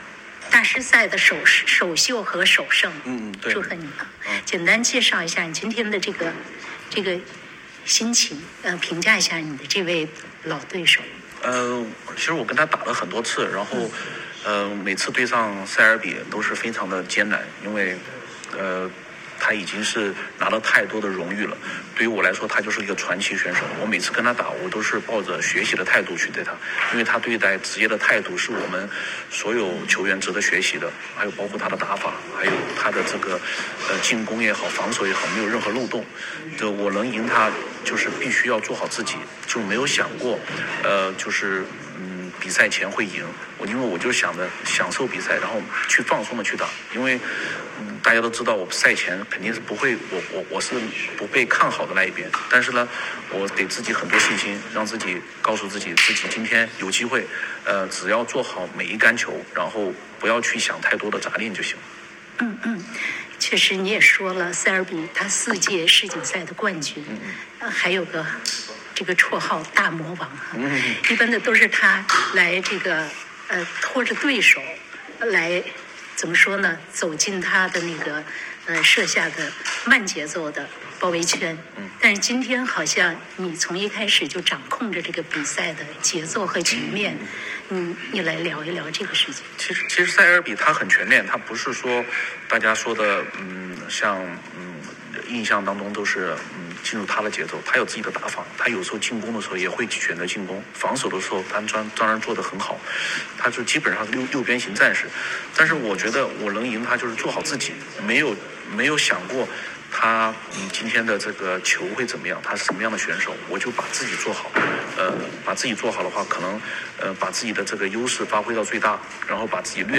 大师赛首轮后采访肖国栋.m4a